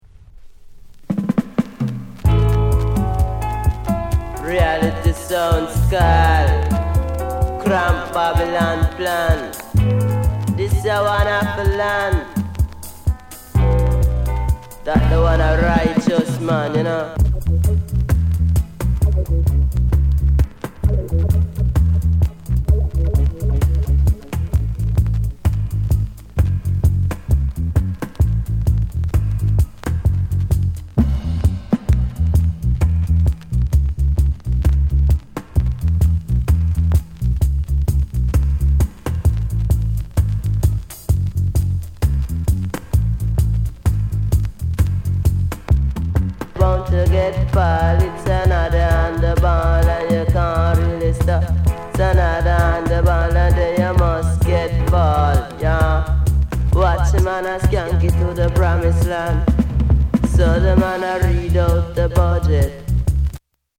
DUB
SOUND CONDITION A SIDE VG